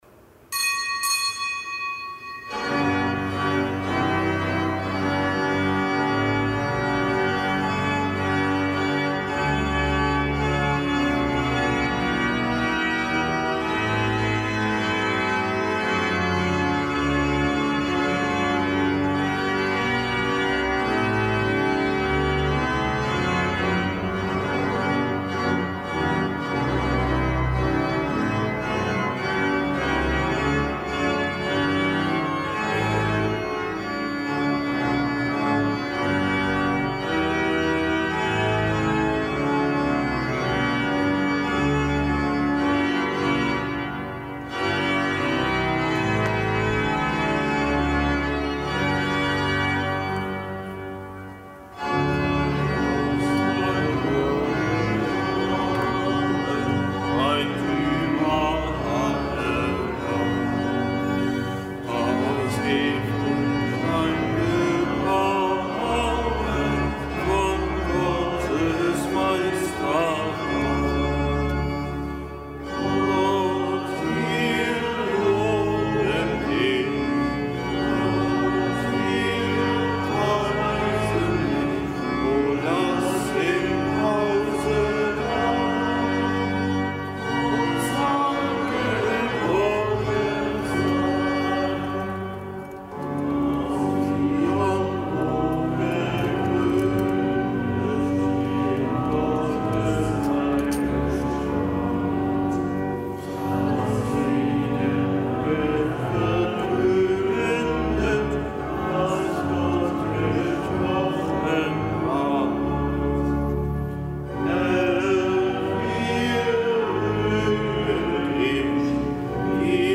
Kapitelsmesse aus dem Kölner Dom am Fest Kathedra Petri. Zelebrant: Weihbischof Rolf Steinhäuser.